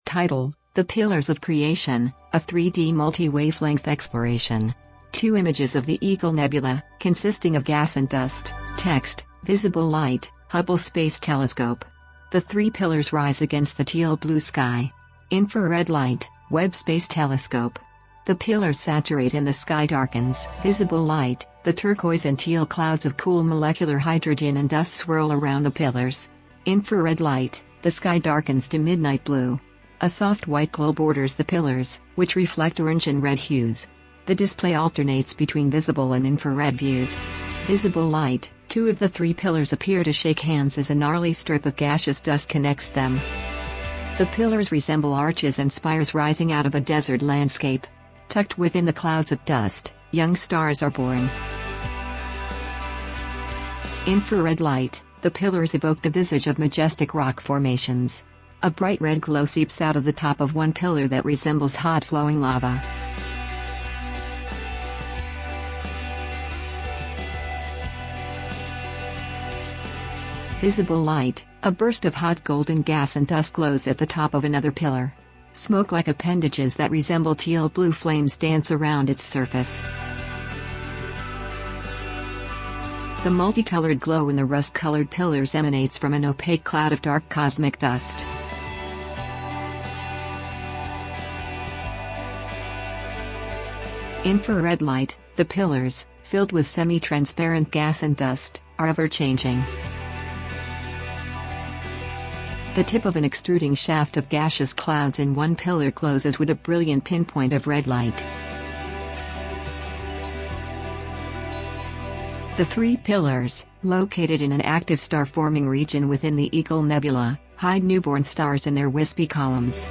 • Audio Description